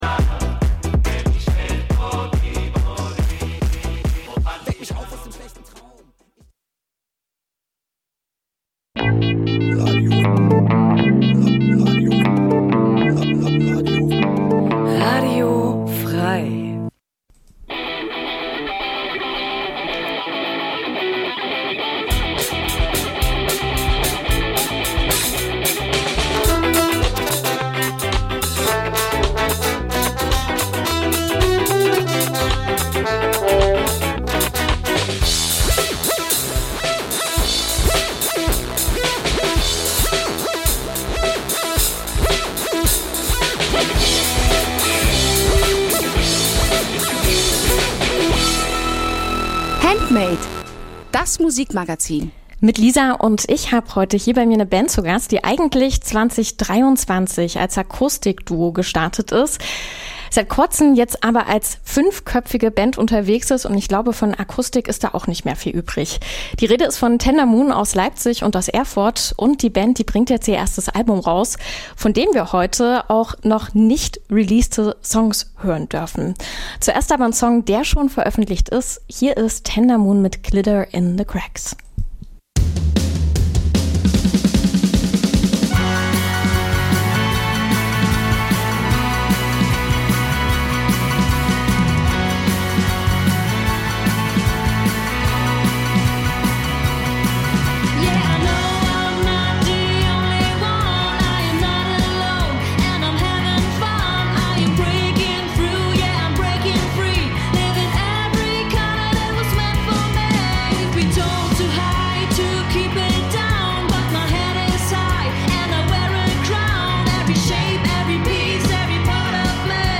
Jeden Donnerstag stellen wir euch regionale Musik vor und scheren uns dabei nicht um Genregrenzen.